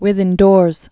(wĭth-ĭndôrz, wĭth-)